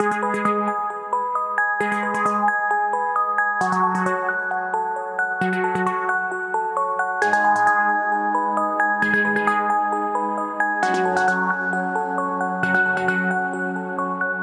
未来系の宇宙っぽいシンセとオルゴールをミックスした一曲です！
ループ：◎
BPM：133
キー：G#m
ジャンル：おしゃれ、みらい
楽器：シンセサイザー、オルゴール